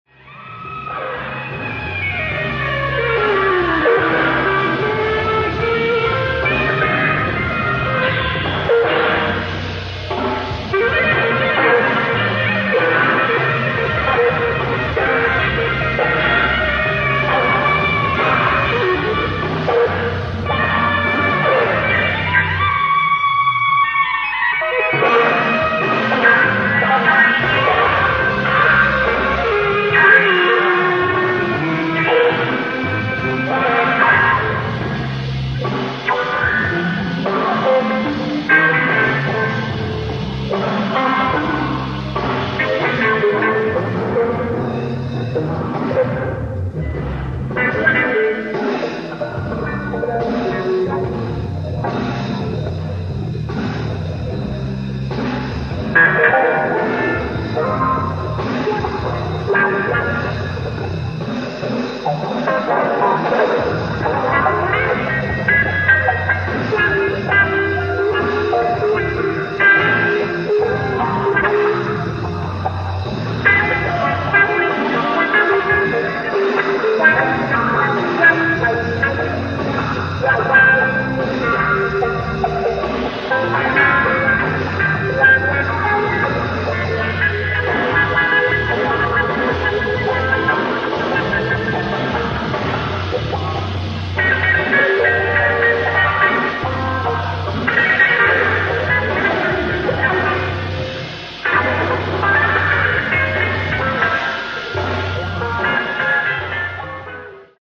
ライブ・アット・デ・ドゥーレン、ロッテルダム、オランダ 11/17/1973
海外マニアによるピッチ修正済レストア音源を初収録！！
※試聴用に実際より音質を落としています。